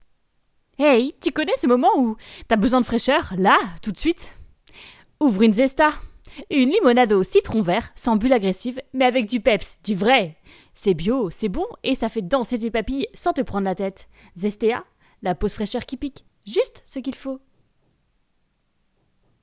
Voix off pub